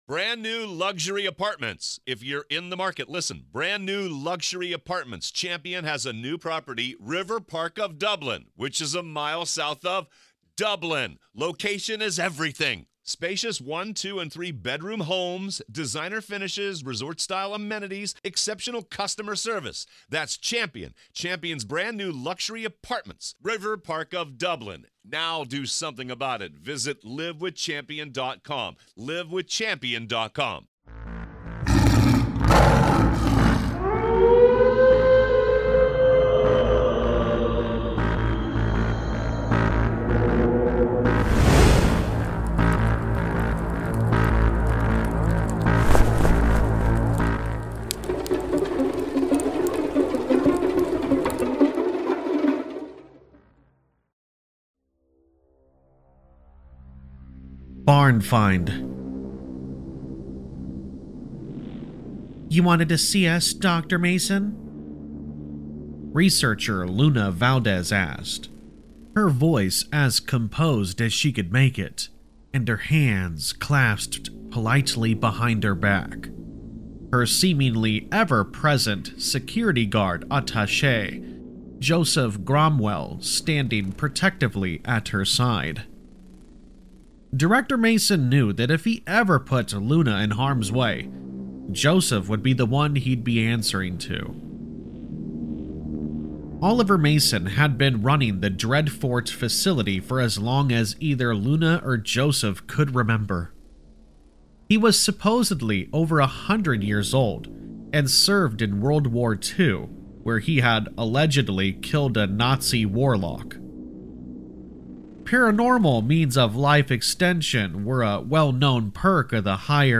In The Barn Find, a secret Government team stumbles upon a chilling secret locked away for decades—one that blurs the line between true scary stories and unspeakable nightmares. Perfect for fans of deep woods horror stories, this disturbing tale unfolds with slow-burn dread and atmospheric terror.
All Stories are read with full permission from the authors: